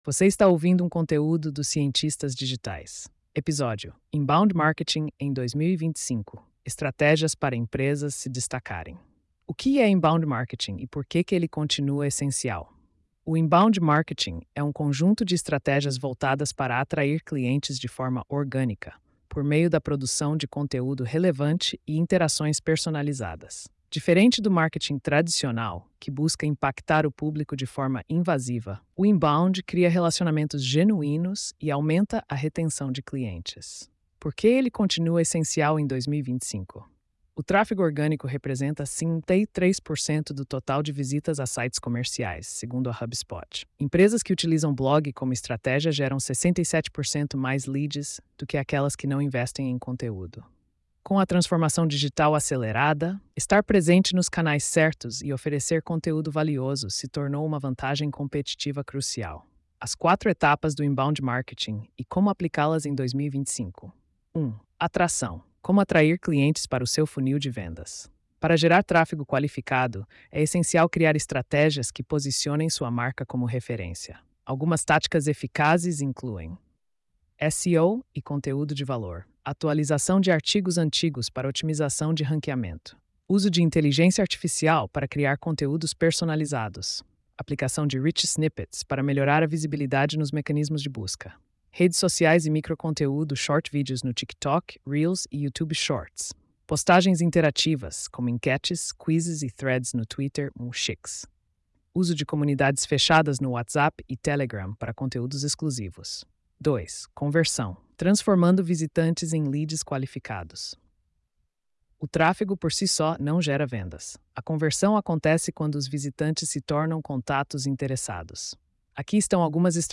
post-2713-tts.mp3